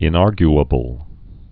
(ĭn-ärgy-ə-bəl)